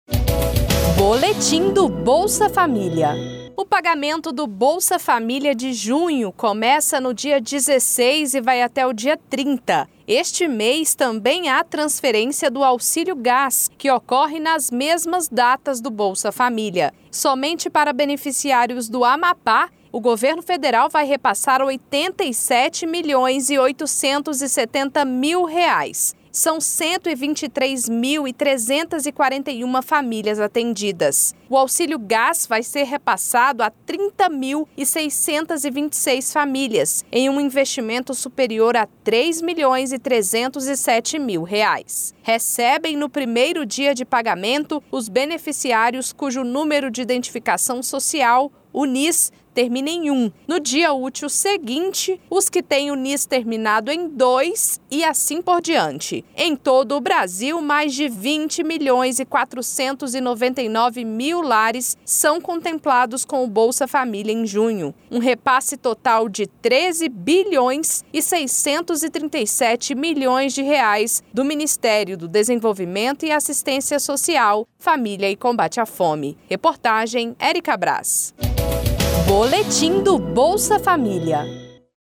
Boletim sobre o calendário de pagamento do Bolsa Família e do Auxílio Gás em abril de 2025.